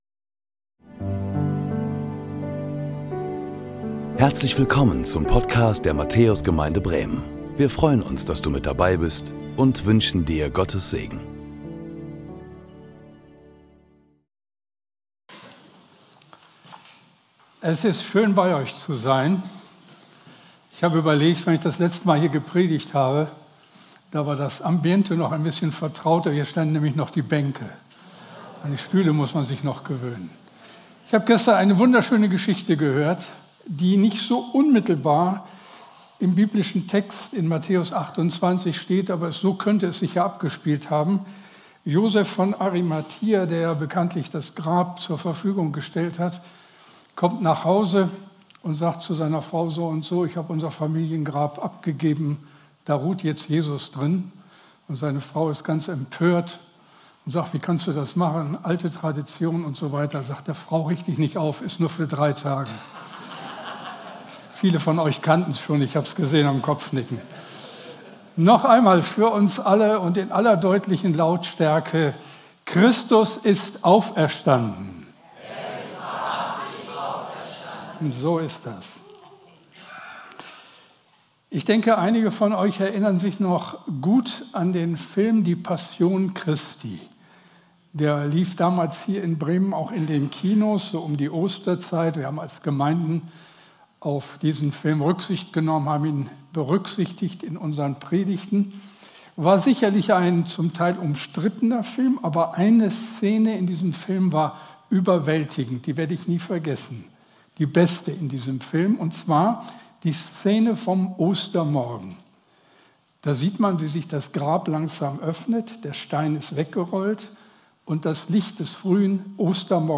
Predigten der Matthäus Gemeinde Bremen Ostermontag "Vollmacht!"